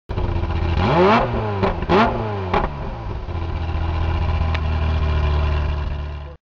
甲高いV8の音。
せっかくですからF355の音は